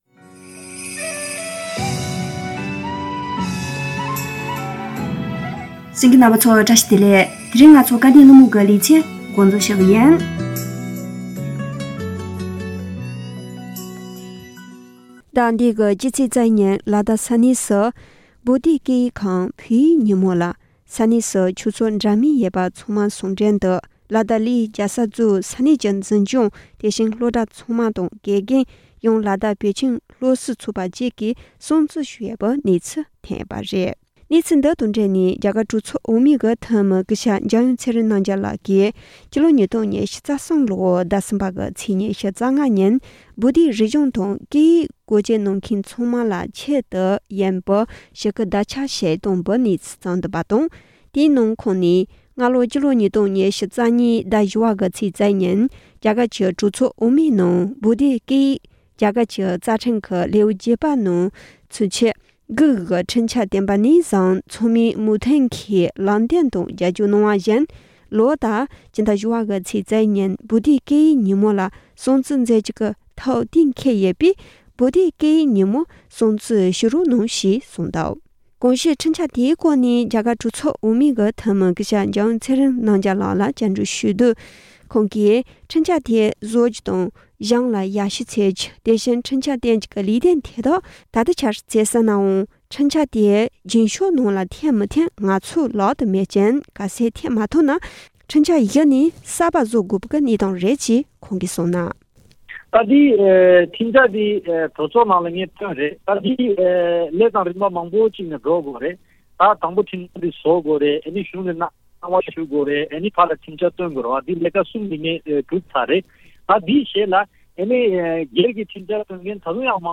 སྐབས་དོན་གླེང་མོལ་གྱི་ལེ་ཚན་ནང་།